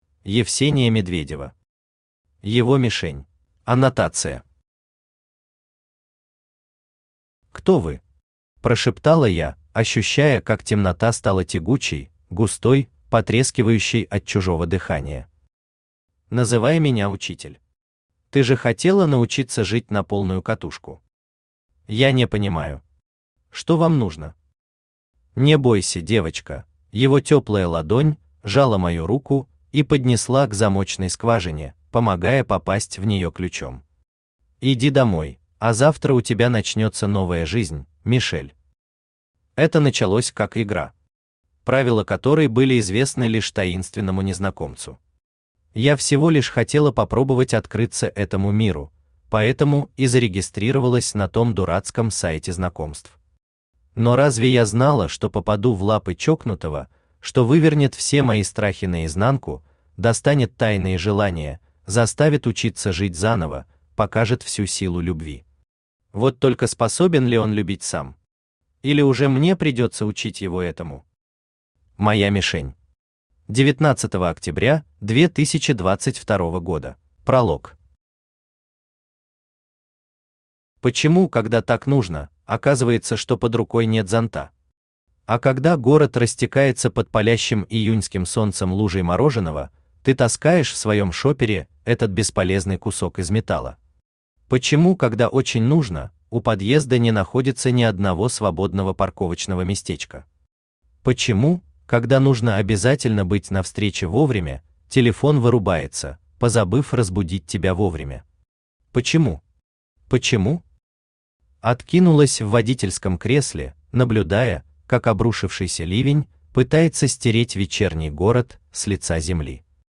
Аудиокнига Его Мишень | Библиотека аудиокниг
Aудиокнига Его Мишень Автор Евсения Медведева Читает аудиокнигу Авточтец ЛитРес.